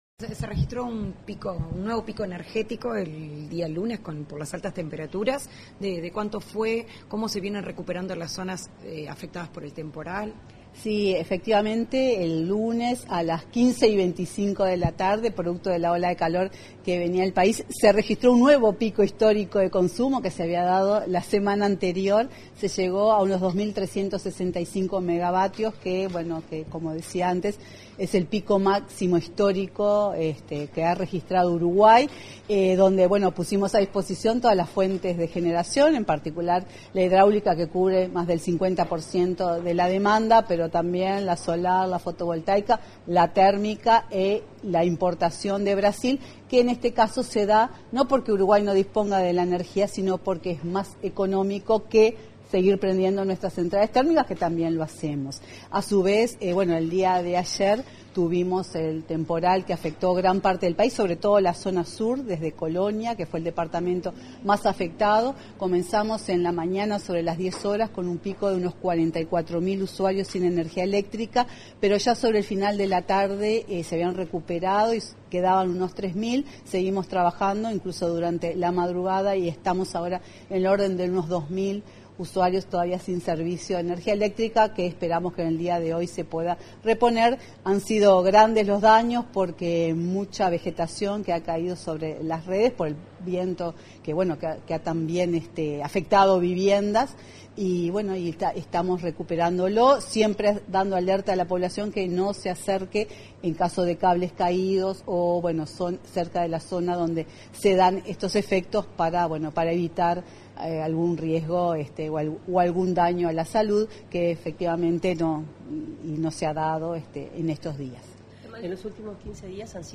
Declaraciones de la presidenta de UTE, Silvia Emaldi
Declaraciones de la presidenta de UTE, Silvia Emaldi 12/02/2025 Compartir Facebook X Copiar enlace WhatsApp LinkedIn Luego de la firma de la segunda adenda del memorando de entendimiento de interconexión eléctrica entre Uruguay y Brasil, este 12 de febrero, la presidenta de la UTE, Silvia Emaldi, efectuó declaraciones a la prensa.